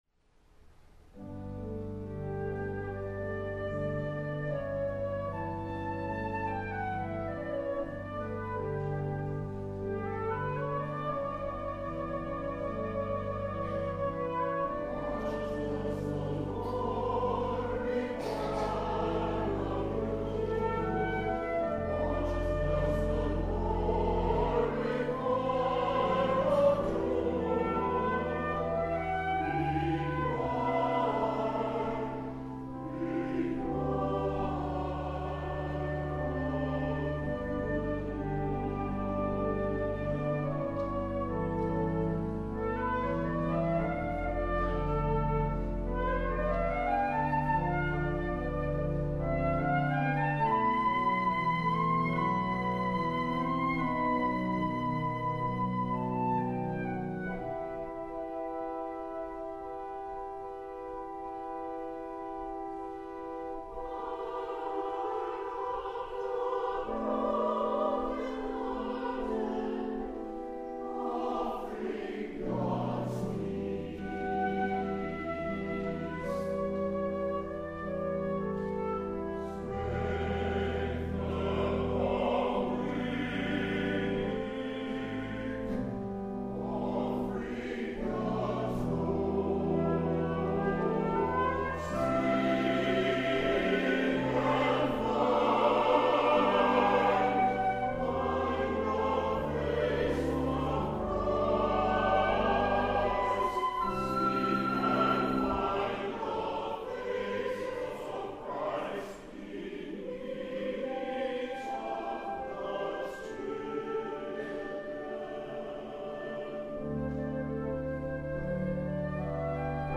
Chancel Choir
In 2013, the premier presentation of What does the Lord require of you? was presented by the chancel choir.